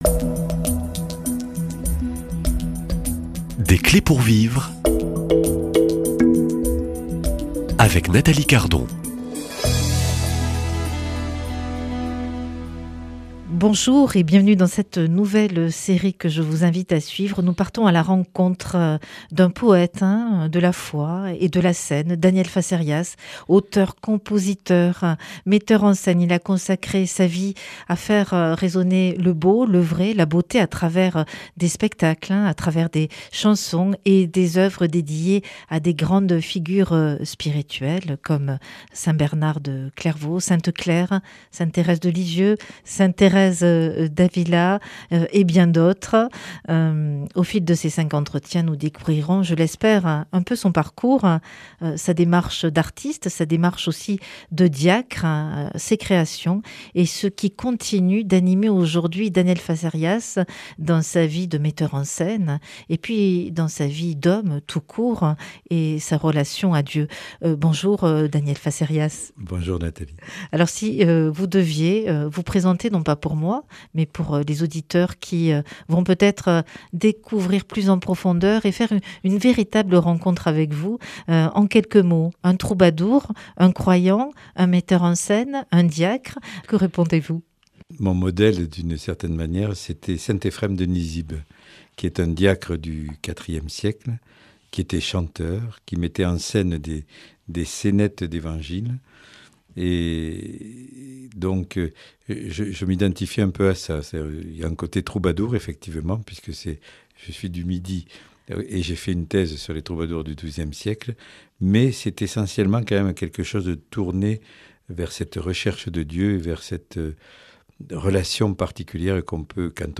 Au fil de ces cinq entretiens, nous découvrirons son parcours, sa démarche, ses créations et ce qui continue de l’animer aujourd’hui.